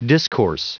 Prononciation du mot discourse en anglais (fichier audio)
Prononciation du mot : discourse